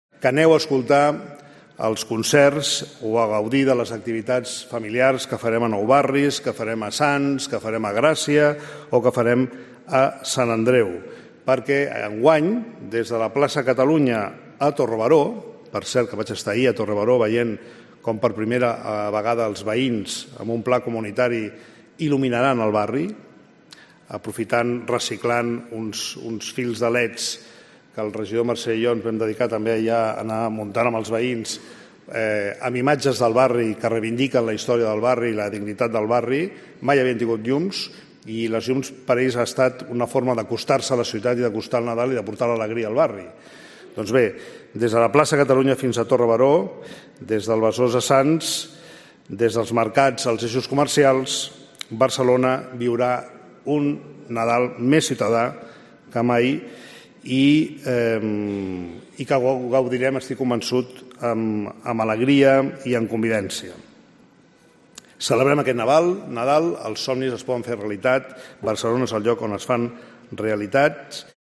Jaume Collboni, alcalde de Barcelona, anuncia los actos navideños en Barcelona